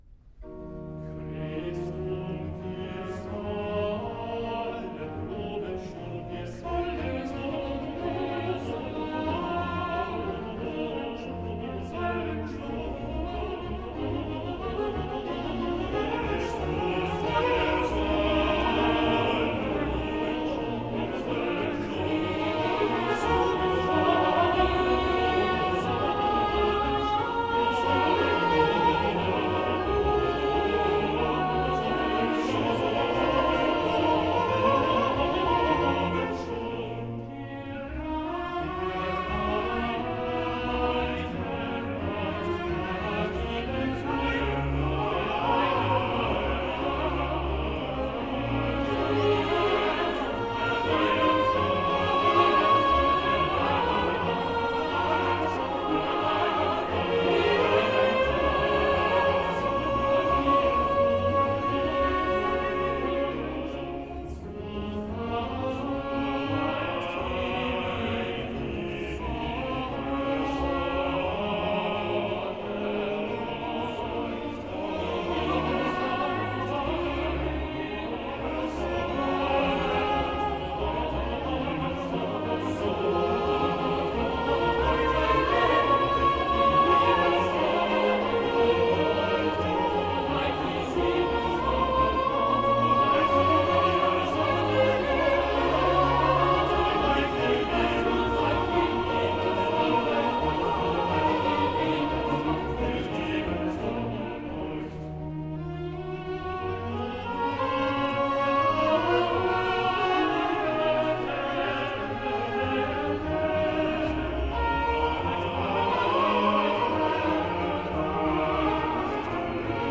Opening Chorus